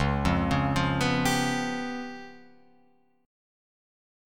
C#7b5 chord